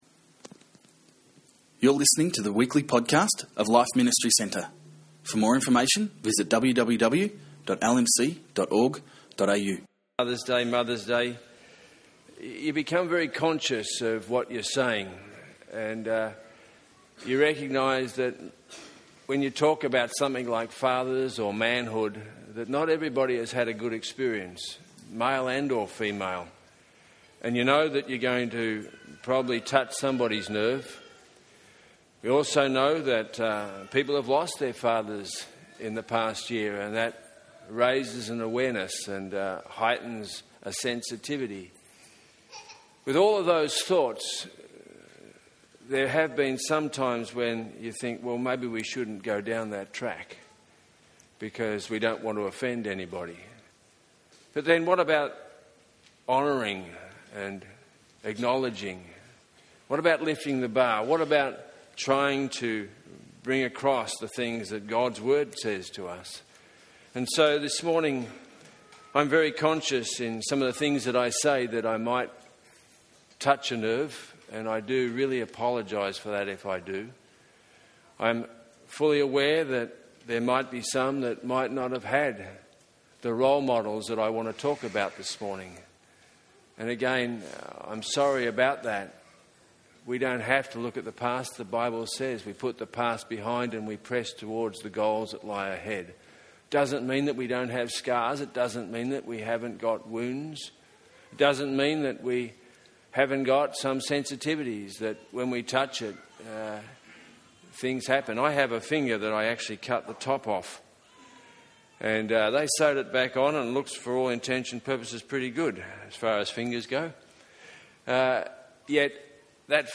In this Father's Day sermon, he reflects on the life of Joshua and how to live a successful life.